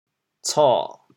co3.mp3